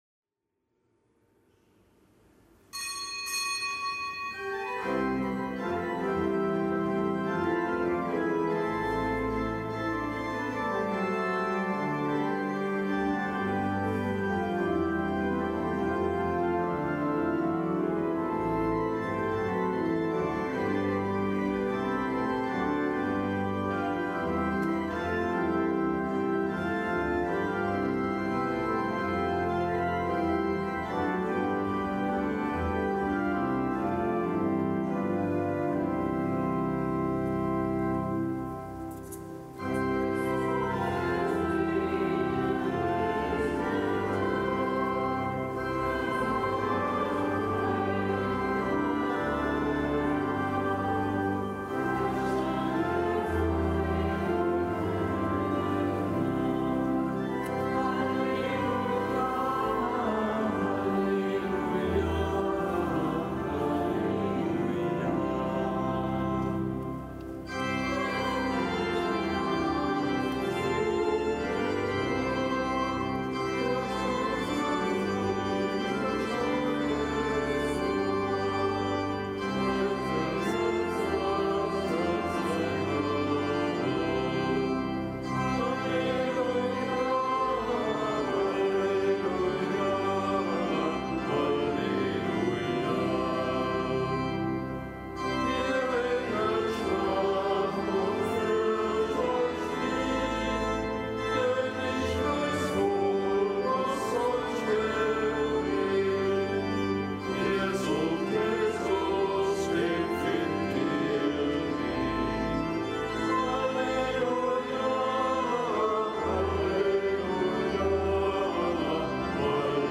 Kapitelsmesse aus dem Kölner Dom am Fest der Heiligen Maria Magdalena; Apostelin der Apostel.